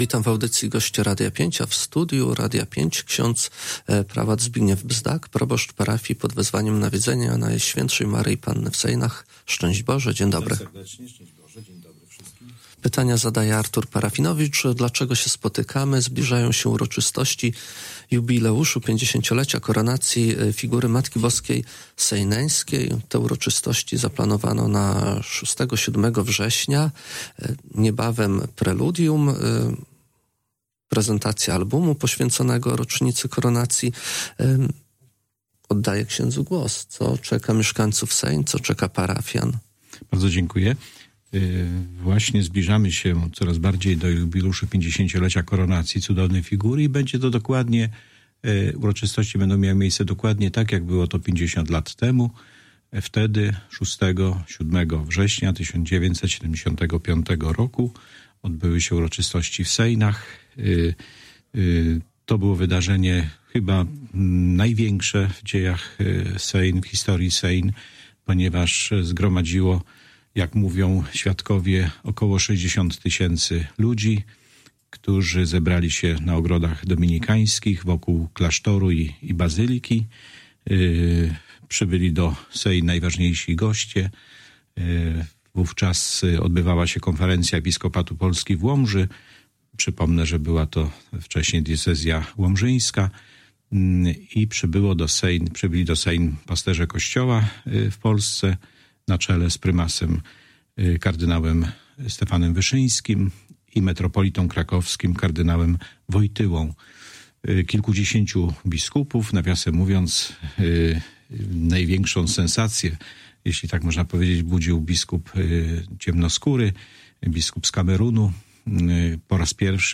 O zbliżającej się rocznicy mówił dziś w Radiu 5 ksiądz